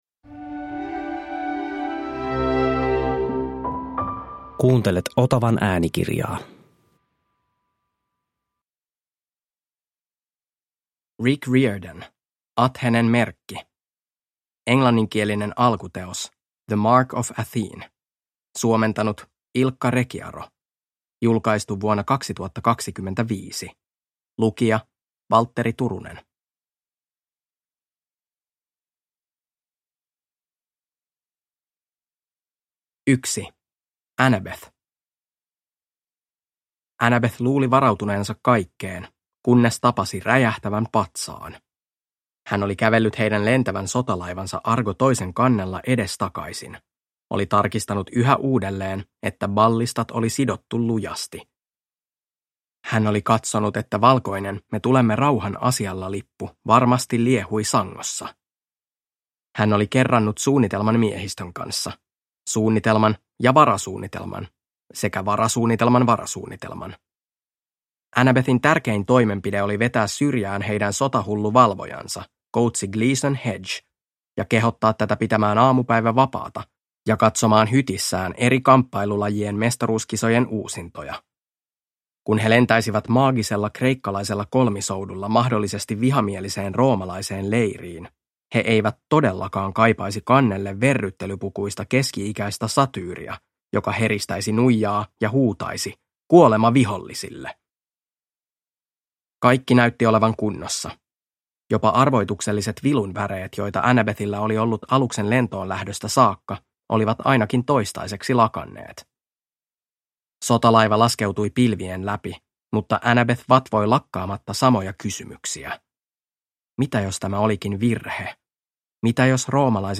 Athenen merkki – Ljudbok